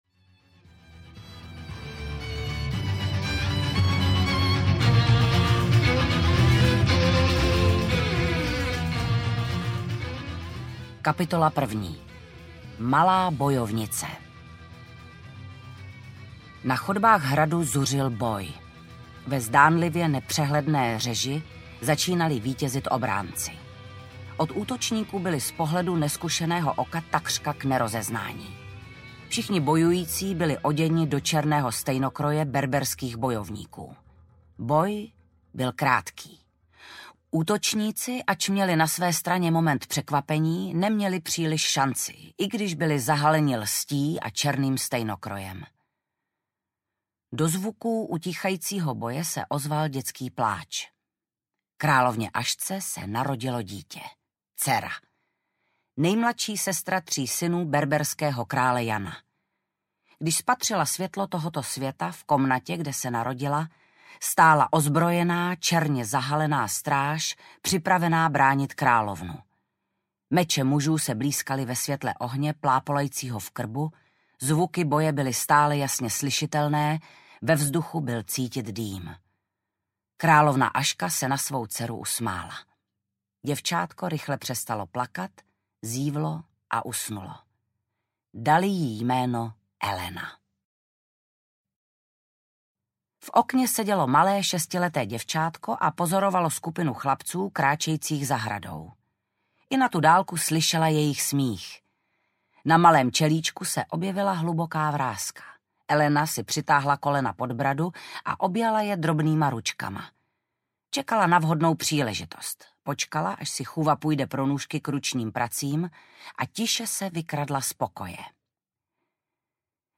Dračí oči - Čarodějka audiokniha
Ukázka z knihy
• InterpretVanda Hybnerová